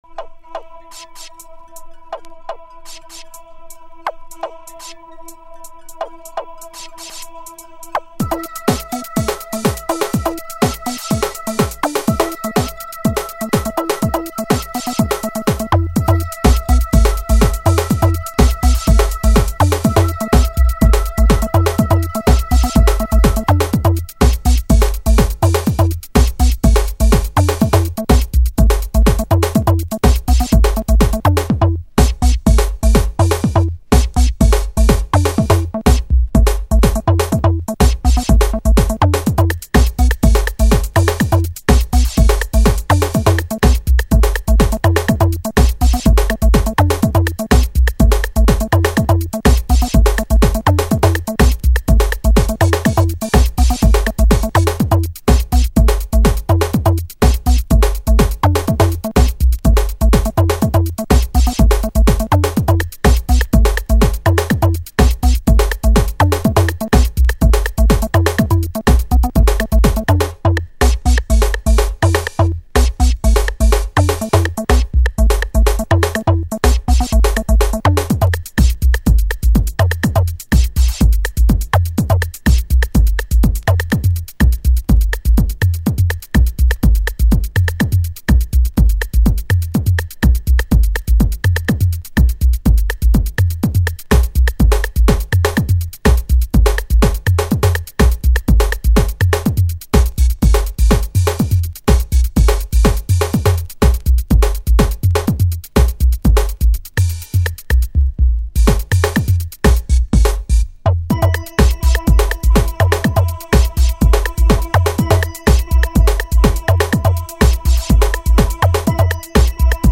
Early House / 90's Techno
ベースも素晴らしい鳴りをします。